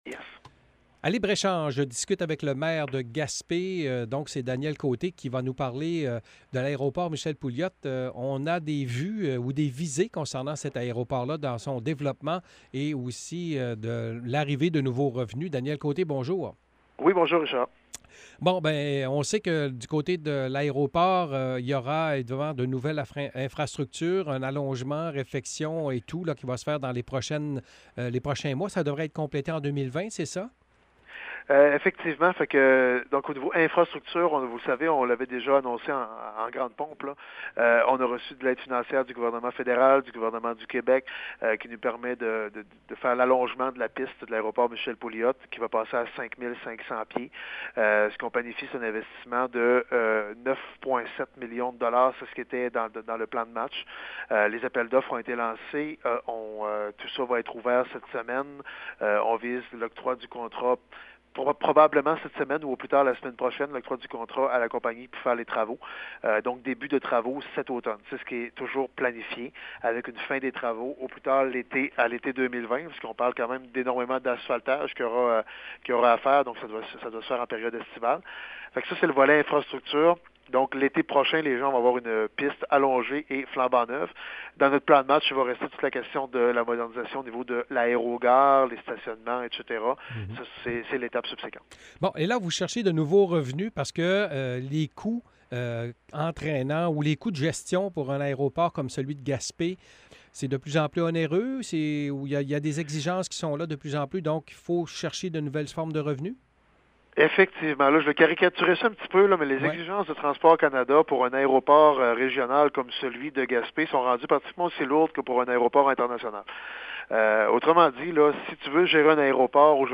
Mercredi à l’émission Libre-Échange, nous avons discuté avec le maire de Gaspé des projets pour l’aéroport Michel Pouliot. La Ville cherche de nouveaux revenus et une autre compagnie aérienne.